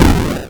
groundCollapse.wav